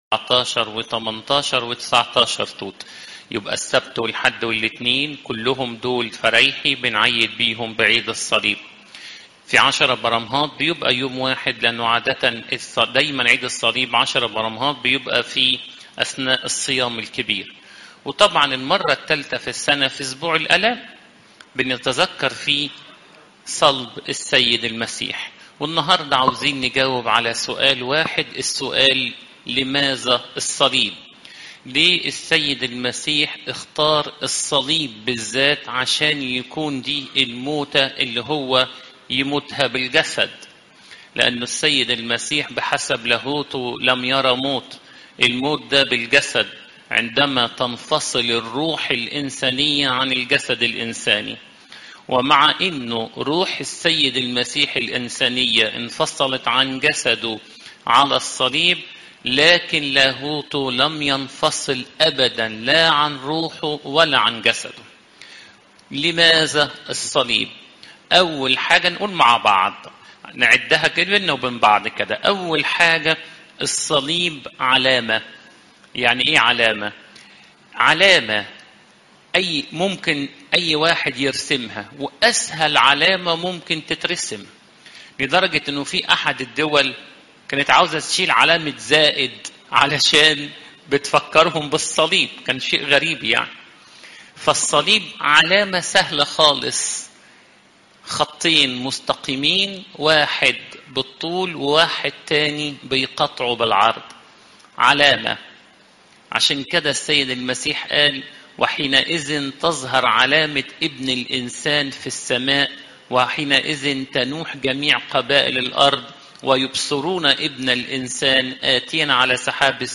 عظات المناسبات
عشية عيد الصليب شهر توت الزيارات